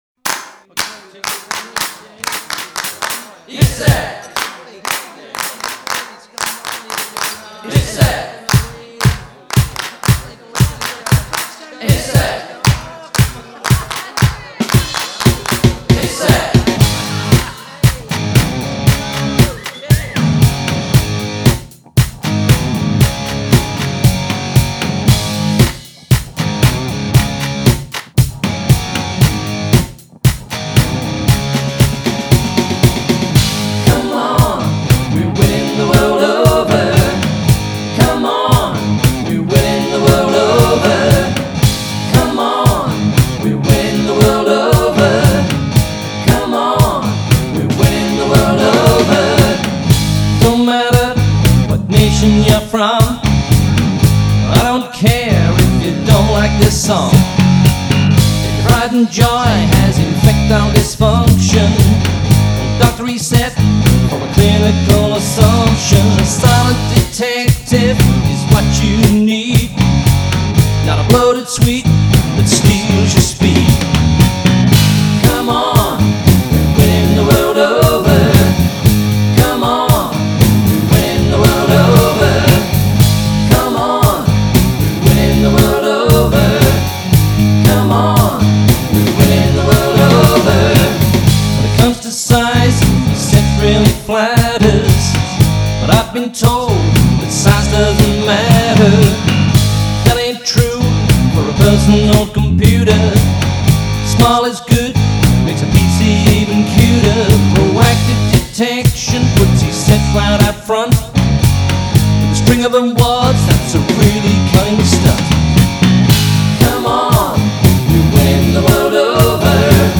las canciones de rock